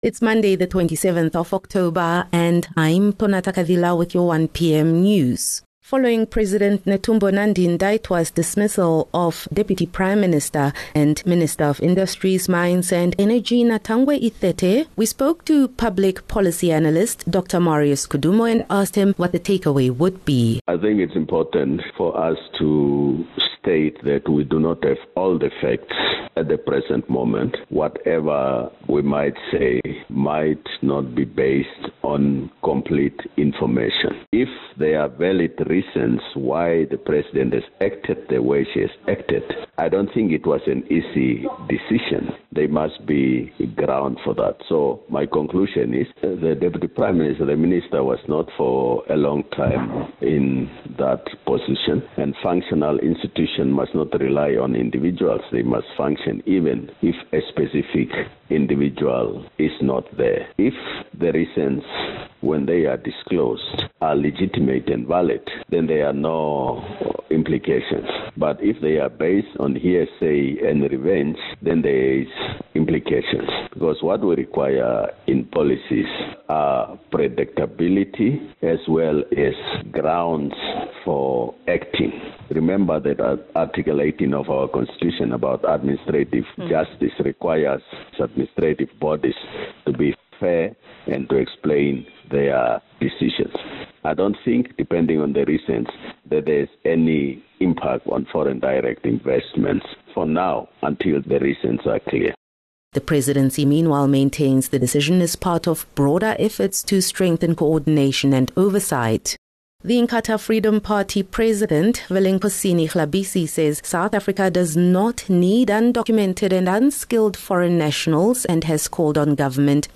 27 October - 1 pm news